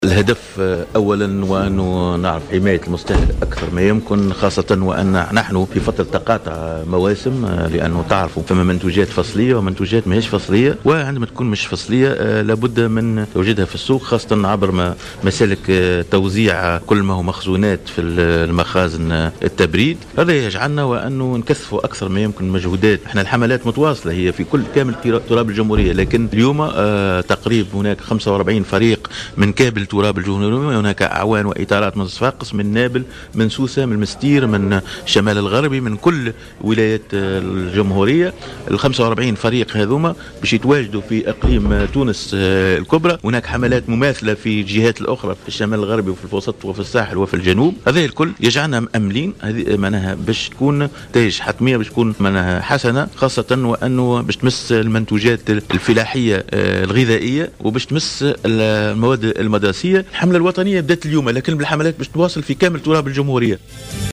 أكد وزير التجارة رضا الأحول اليوم السبت على هامش إشرافه على انطلاق الحملة الوطنية للمراقبة الاقتصادية بسوق الجملة ببئر القصعة أن أسعار بيع أضاحي العيد قد سجلت انخفاضا مقارنة بالعام الماضي.